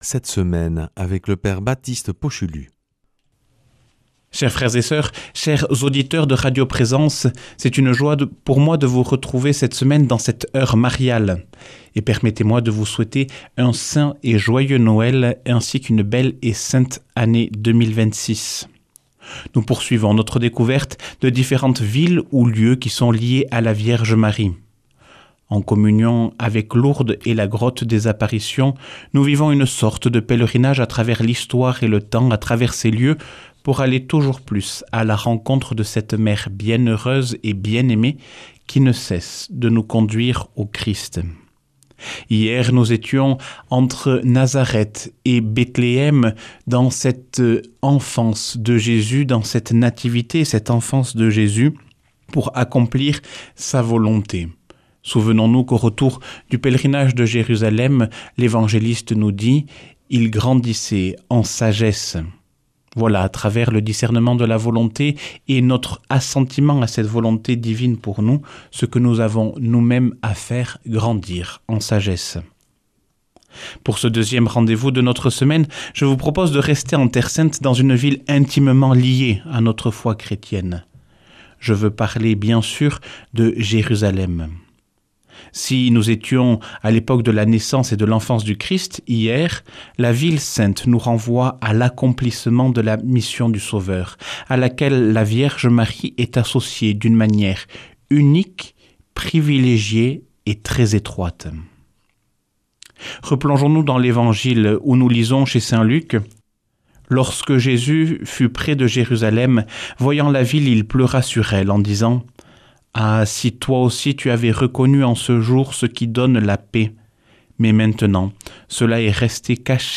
mardi 30 décembre 2025 Enseignement Marial Durée 10 min